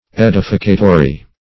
Edificatory \Ed"i*fi*ca`to*ry\, a. Tending to edification.